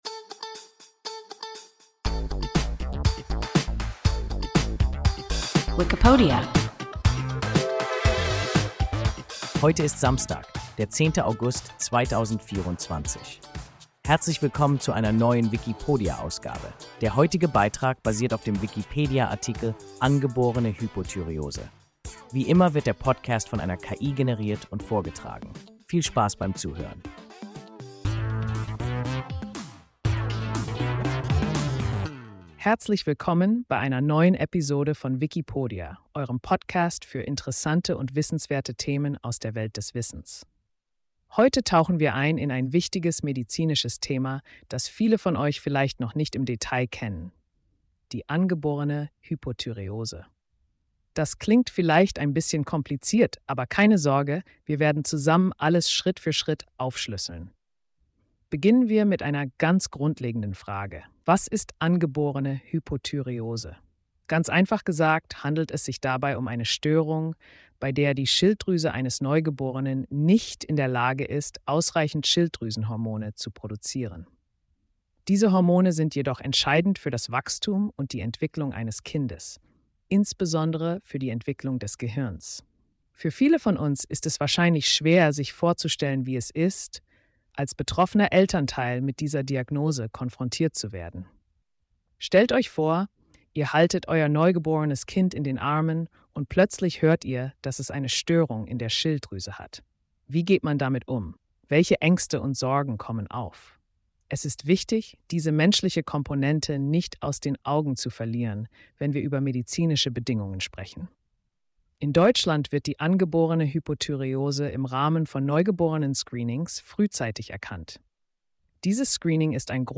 Angeborene Hypothyreose – WIKIPODIA – ein KI Podcast